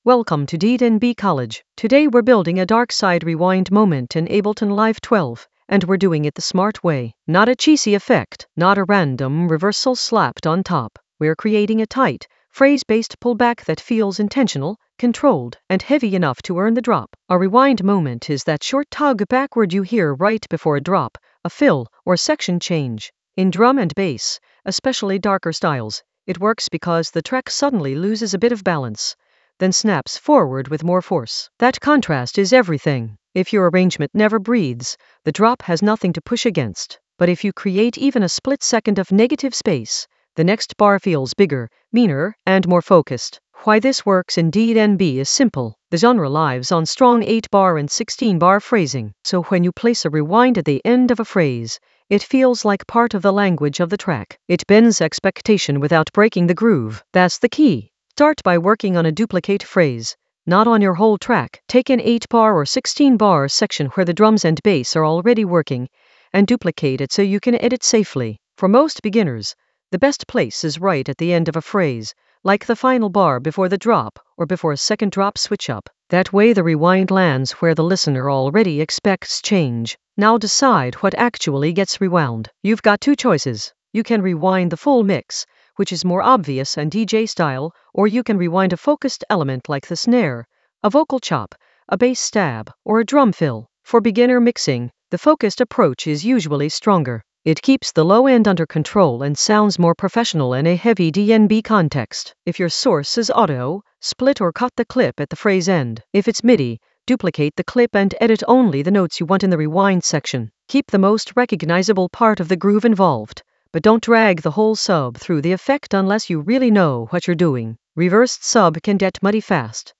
An AI-generated beginner Ableton lesson focused on Darkside approach: a rewind moment tighten in Ableton Live 12 in the Mixing area of drum and bass production.
Narrated lesson audio
The voice track includes the tutorial plus extra teacher commentary.